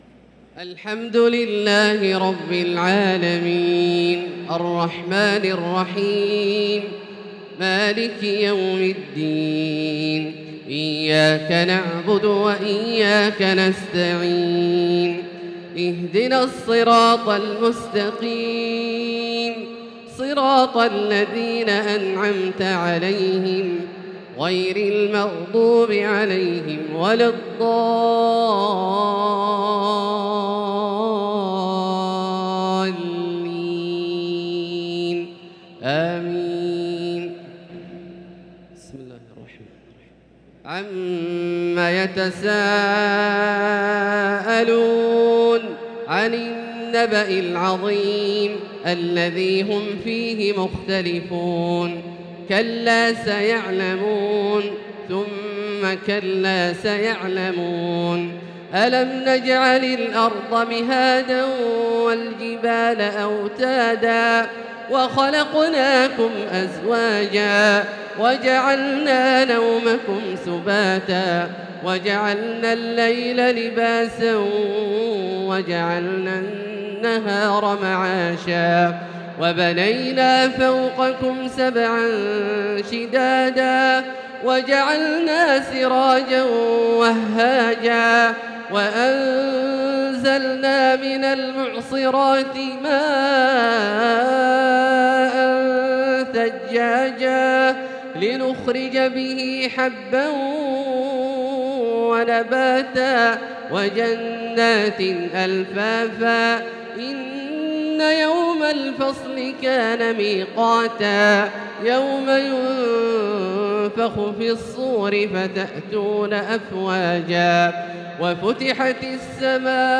تراويح ليلة 29 رمضان 1443هـ من سورة النبأ إلى سورة الكوثر | taraweeh 29 st niqht Ramadan 1443H from Surah An-Naba to Al-Kawthar > تراويح الحرم المكي عام 1443 🕋 > التراويح - تلاوات الحرمين